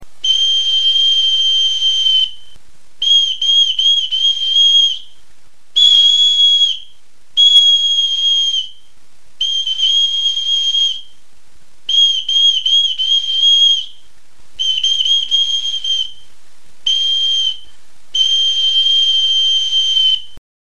Aerófonos -> Flautas -> Bestelakoak
EUROPA -> EUSKAL HERRIA
Erakusketa; hots-jostailuak
Abrikot hezur bat da, ertz bat zulaturik duela.